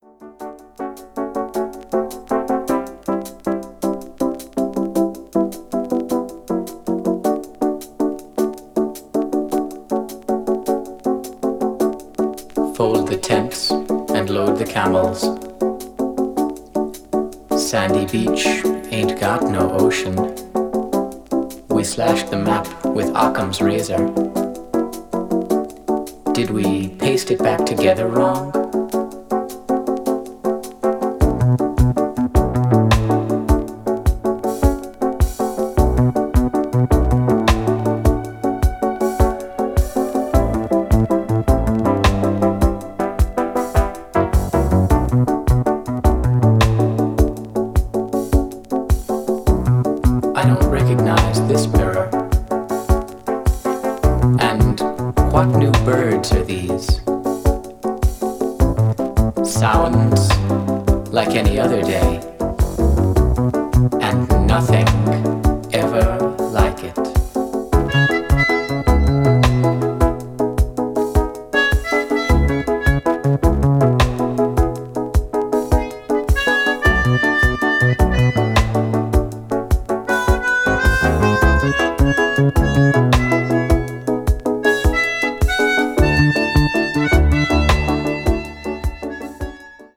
electronic   german new wave   leftfield   synthesizer